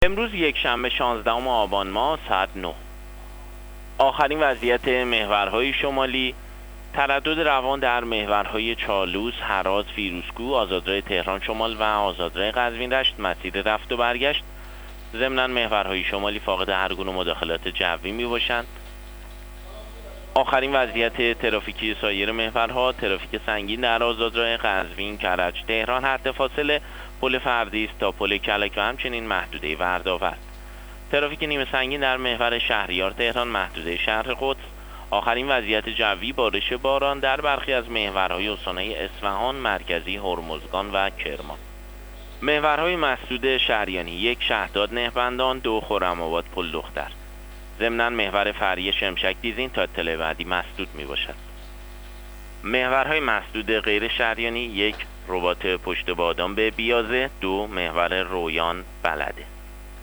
گزارش رادیو اینترنتی از آخرین وضعیت ترافیکی جاده‌ها تا ساعت ۹ شانزدهم آبان؛